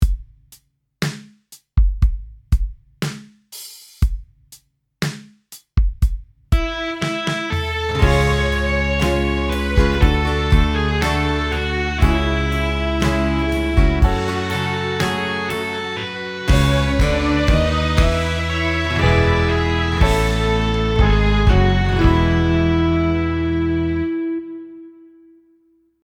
Dạo đầu (Intro)